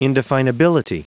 Prononciation du mot indefinability en anglais (fichier audio)
Prononciation du mot : indefinability